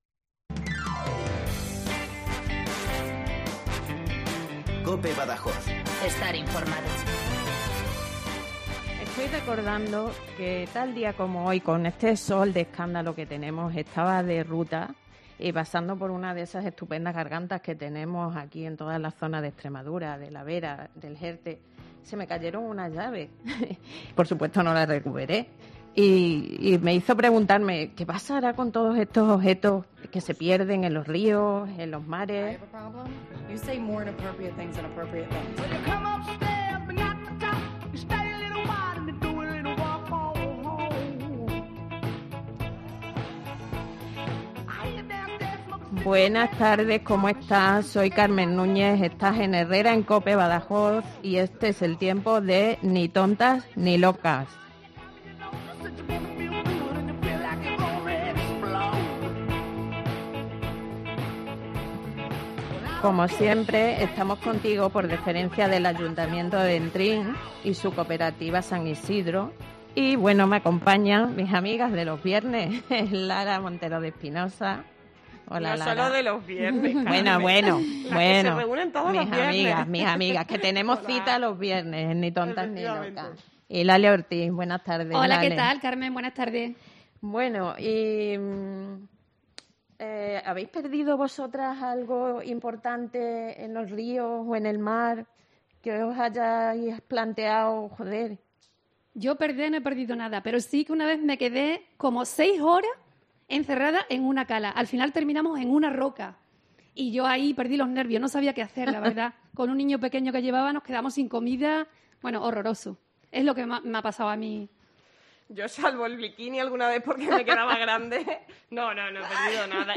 Hoy en Ni Tontas ni Locas en Herrera en Cope Badajoz hemos charlado